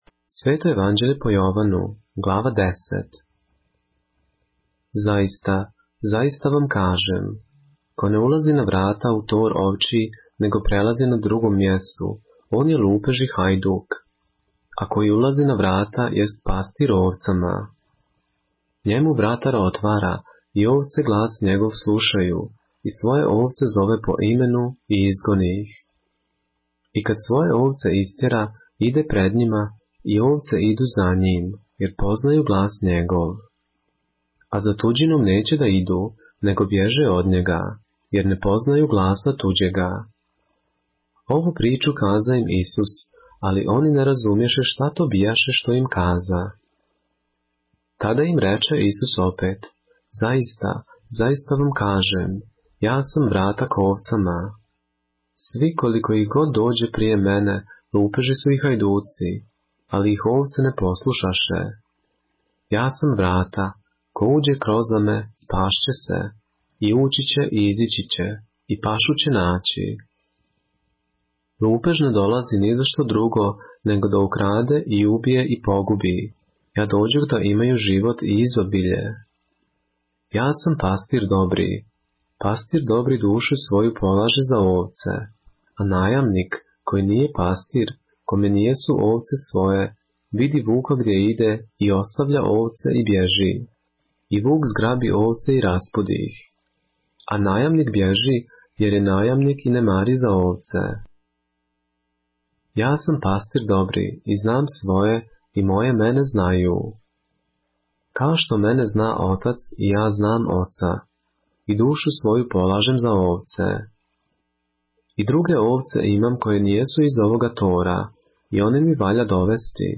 поглавље српске Библије - са аудио нарације - John, chapter 10 of the Holy Bible in the Serbian language